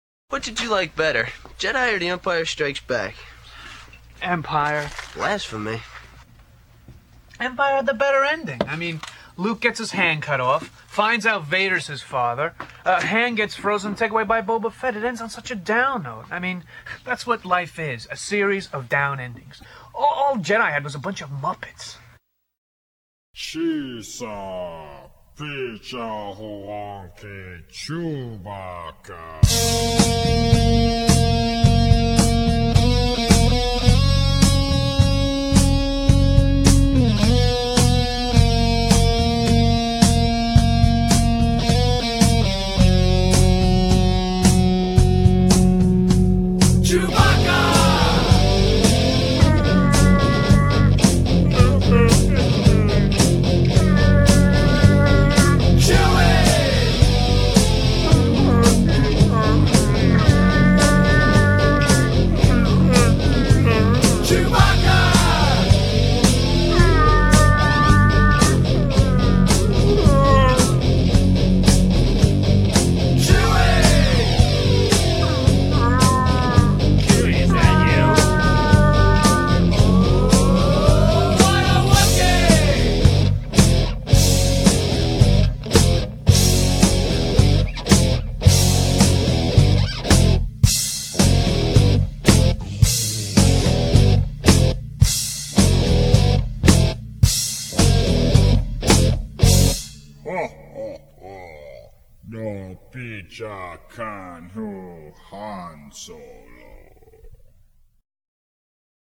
The Empire Strikes Back is best summed up by a short conversation about the merits of The Empire Strikes Back and Return of the Jedi between Dante and Randal from the movie Clerks: